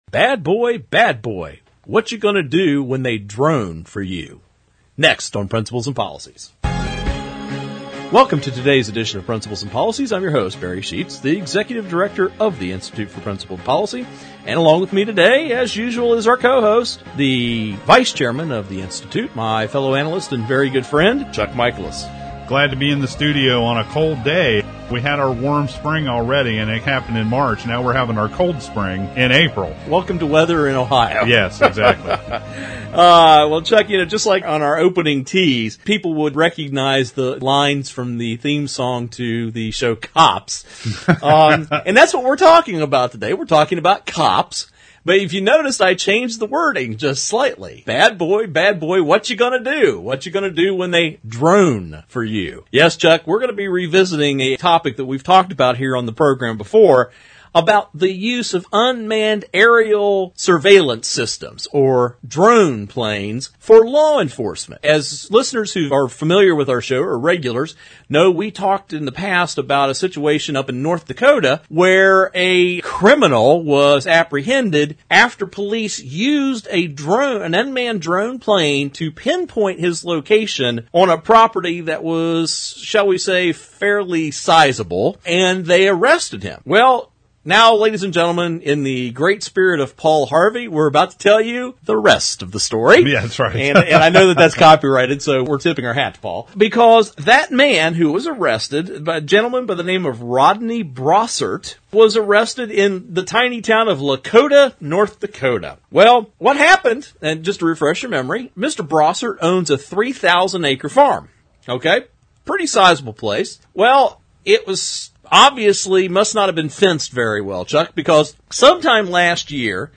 Our Principles and Policies radio show for Friday March 30, 2012.